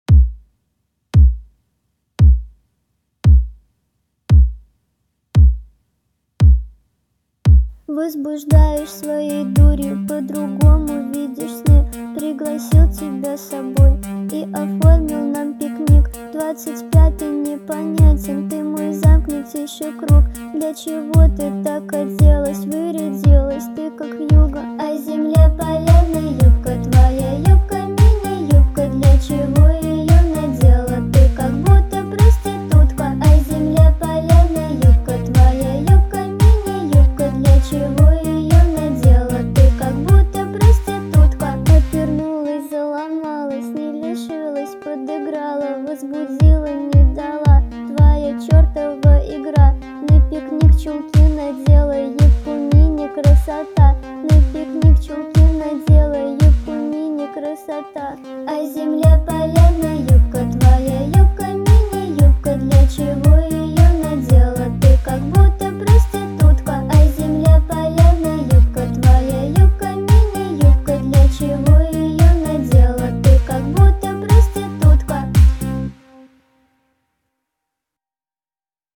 Качество: 320 kbps, stereo
Тик Ток, Рэп